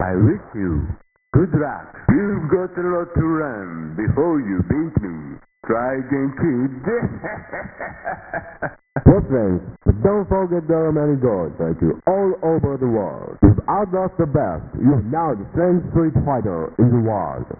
FS-08 Mix: Oooo yah!! OH NOOOOSSS!! BOOOO!! *clapping*
FS-07 Mix: Hadouken, and many other sound effects, grunts, etc.
FS-02 Mix: American speaker listing countries.
You know the mumbly / Engrishy dude that narrates such classical lines such as "What strength, but there are many more fighters like you all ovah da world".